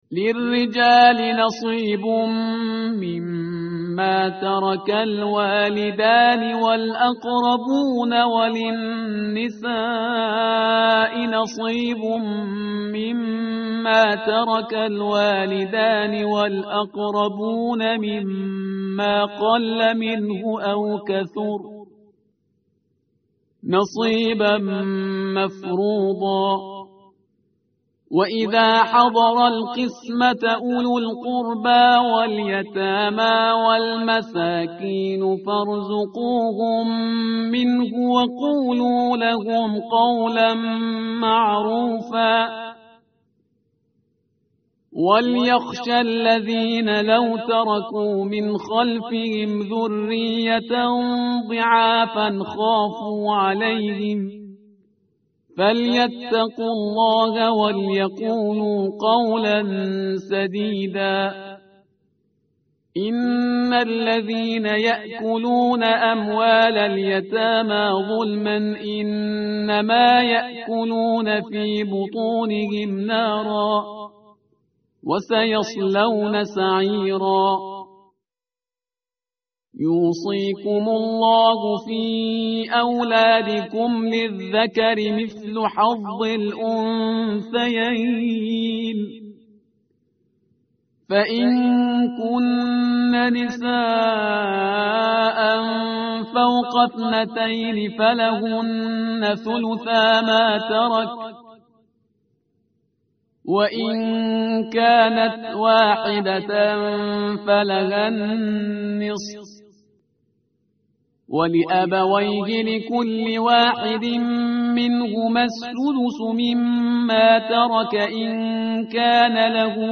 tartil_parhizgar_page_078.mp3